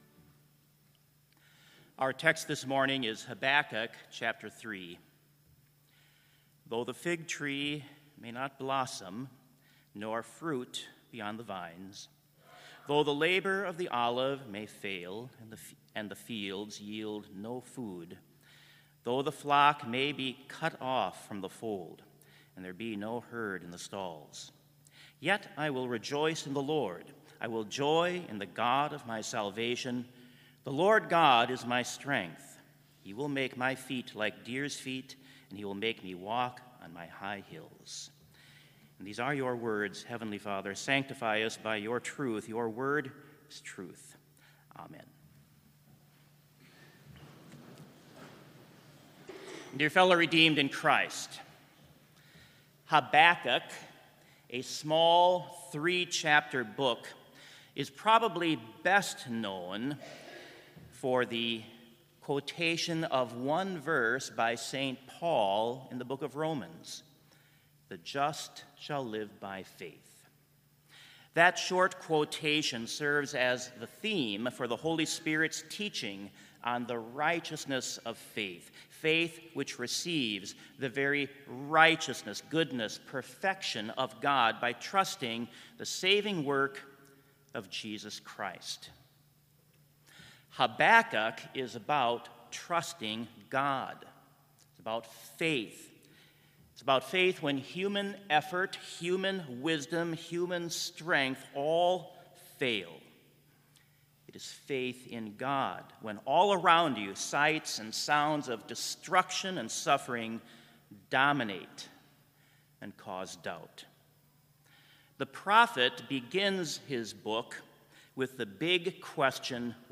Complete service audio for Chapel - September 25, 2019